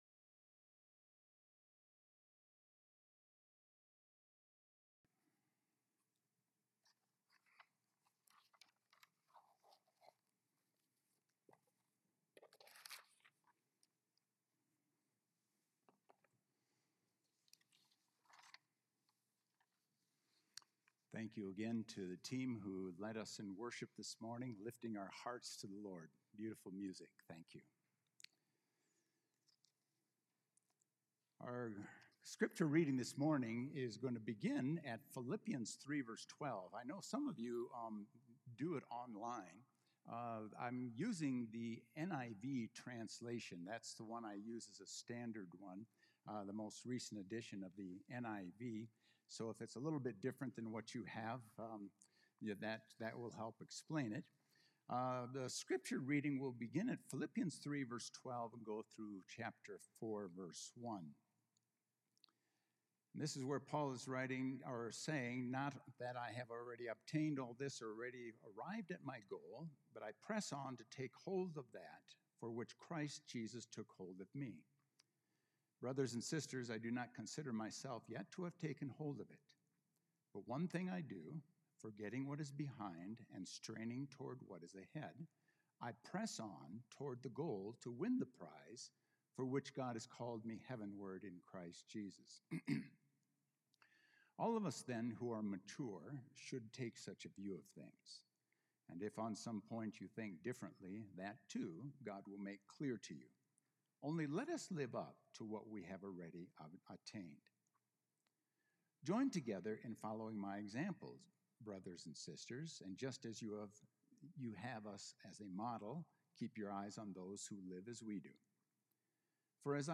A message from the series "Philippians